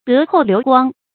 德厚流光 注音： ㄉㄜˊ ㄏㄡˋ ㄌㄧㄨˊ ㄍㄨㄤ 讀音讀法： 意思解釋： 德：道德，德行；厚：重；流：影響；光：通「廣」。